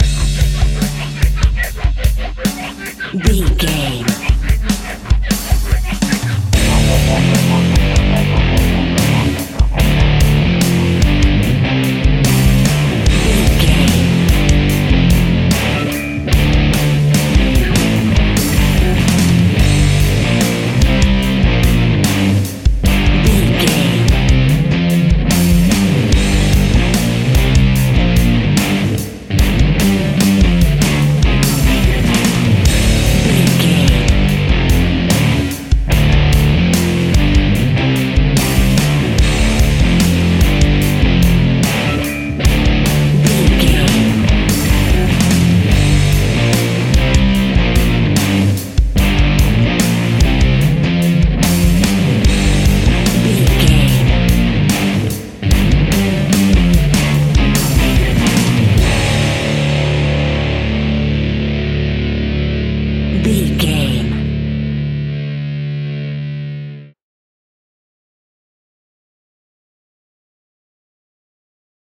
Fast paced
Aeolian/Minor
heavy metal
blues rock
Rock Bass
Rock Drums
heavy drums
distorted guitars
hammond organ